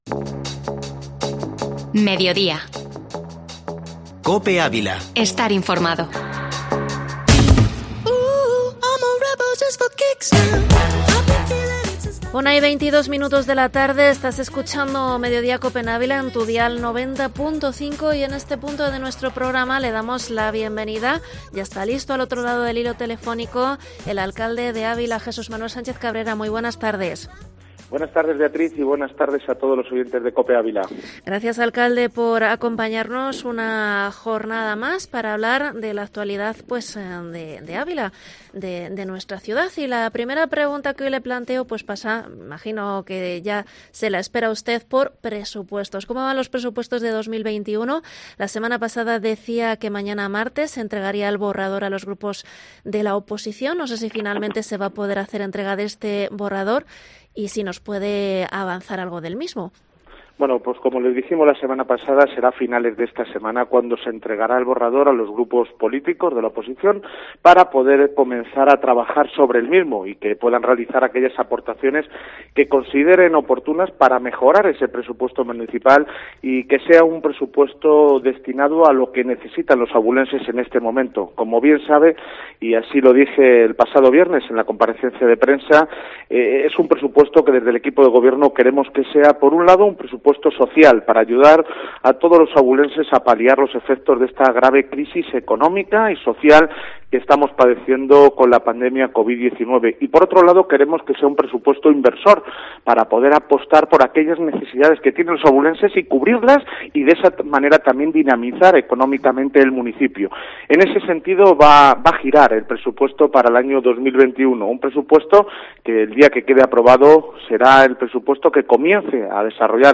Entrevista alcalde de Ávila, Jesús Manuel Sánchez Cabrera 22/02/2021